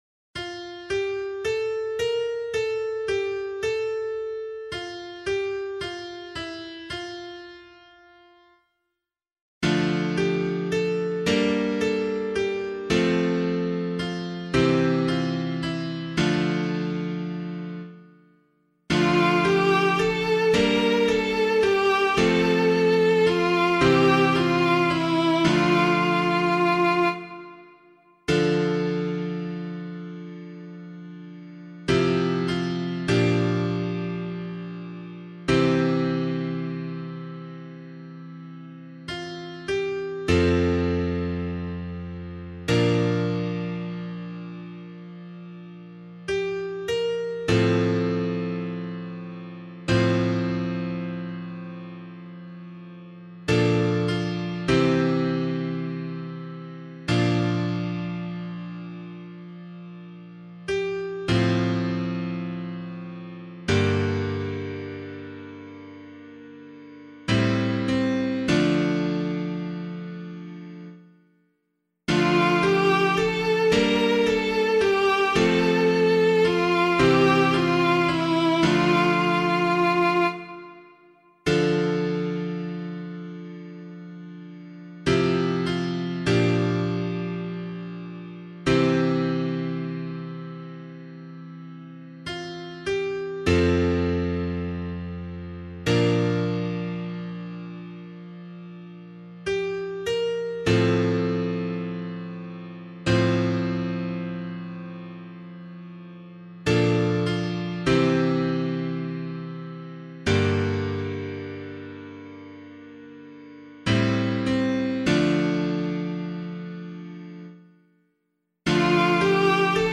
040 Ordinary Time 6 Psalm C [APC - LiturgyShare + Meinrad 1] - piano.mp3